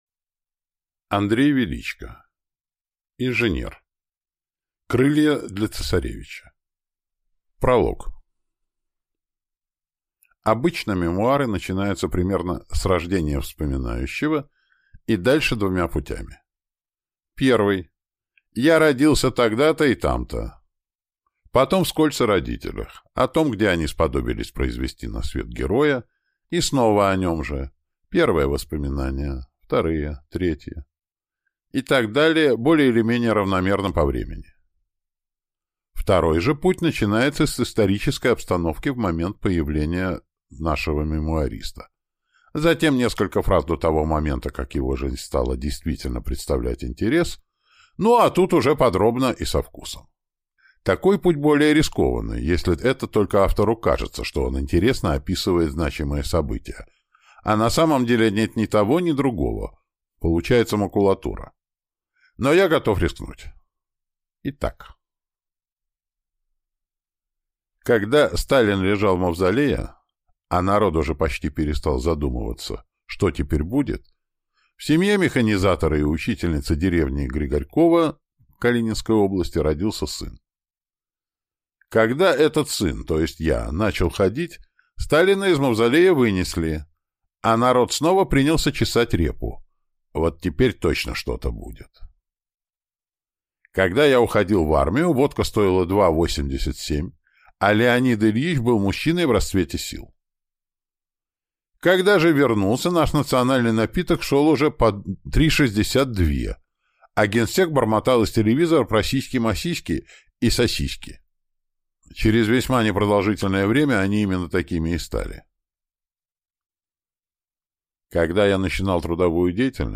Аудиокнига Инженер. «Крылья» для цесаревича | Библиотека аудиокниг